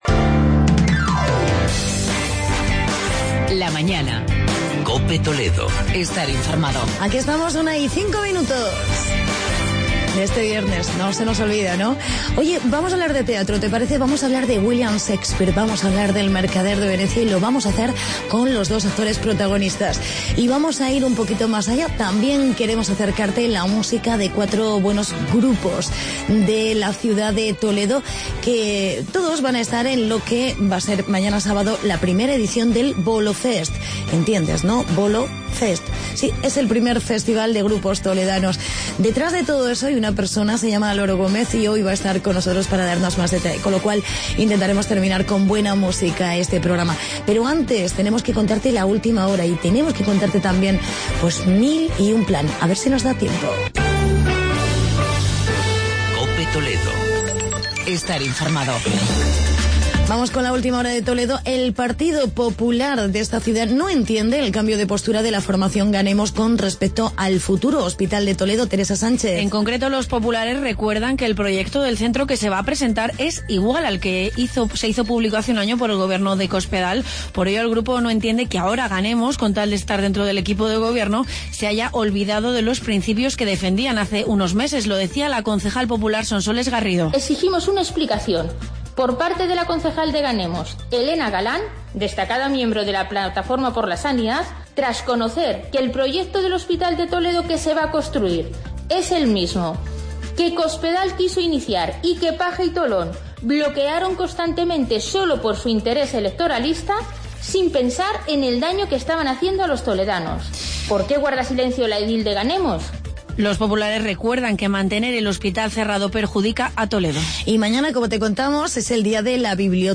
Reportaje sobre "El Mercader de Venecia"